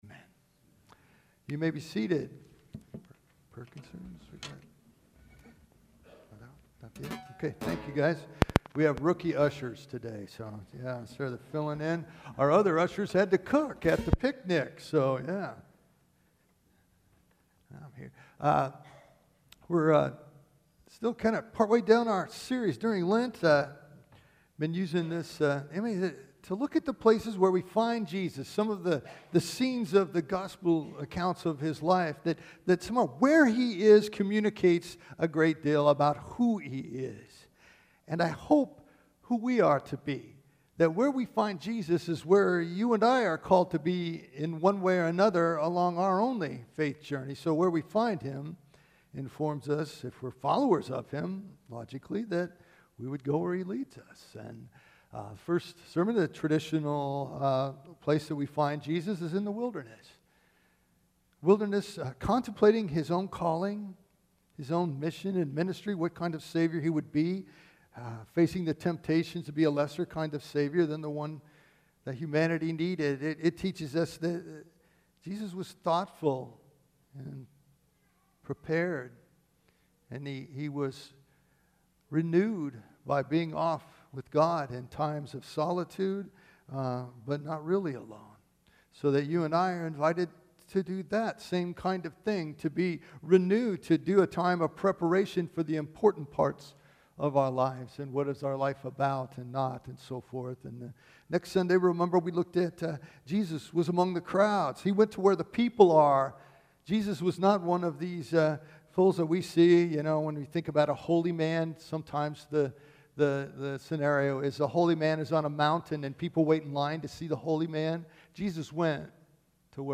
Fourth Sunday of Lent.